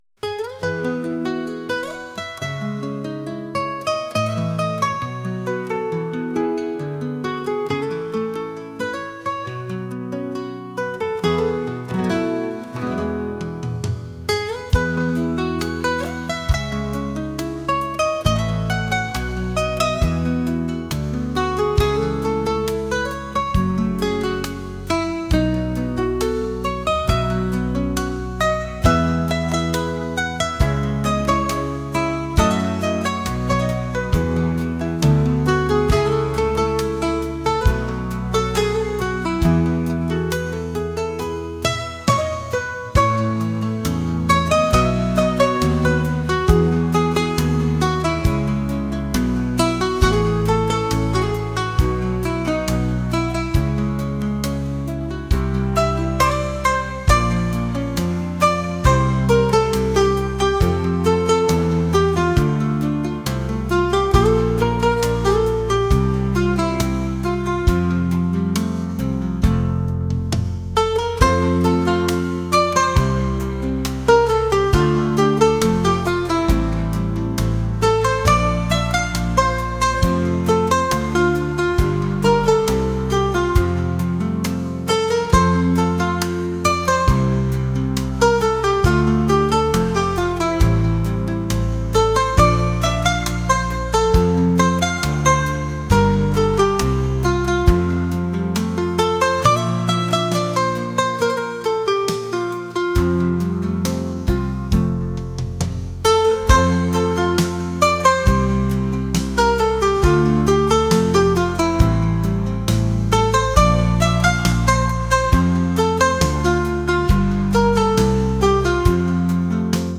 pop | acoustic | soul & rnb